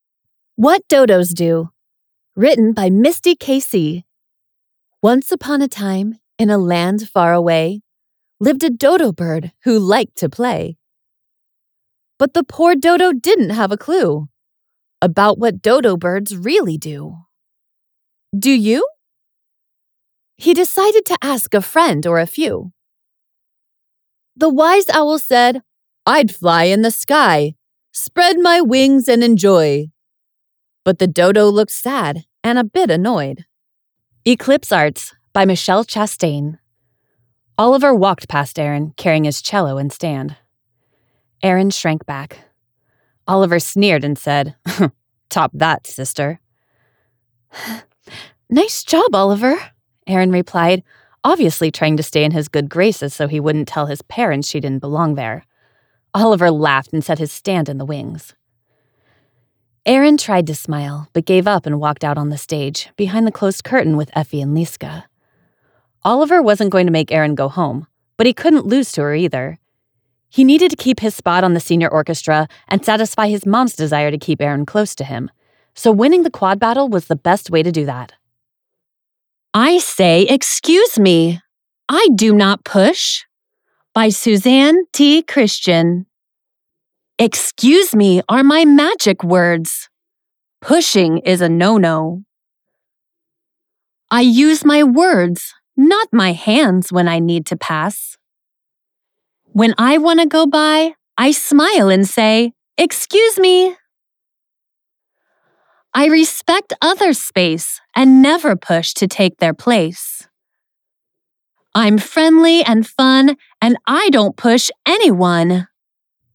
Professional Audiobook Voice Over Artists | The Voice Realm